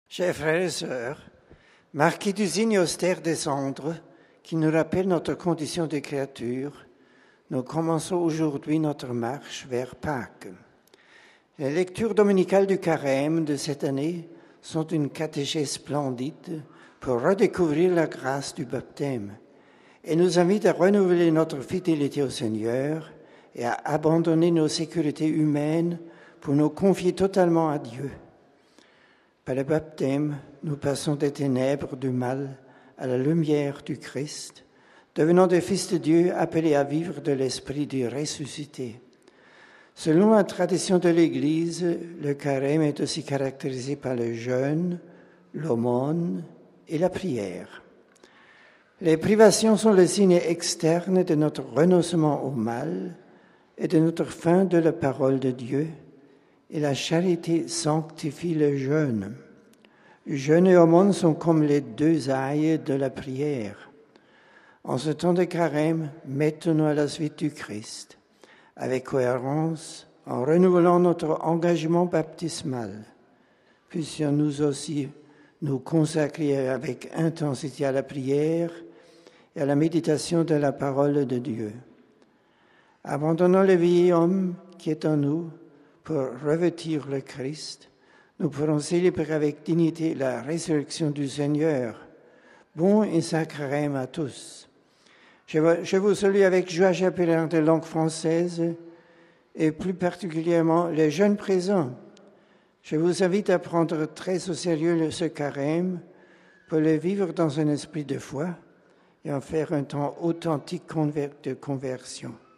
Écoutez le Pape s'adresser aux fidèles francophones venus participer à l'audience générale dans la salle Paul VI ce mercredi matin RealAudio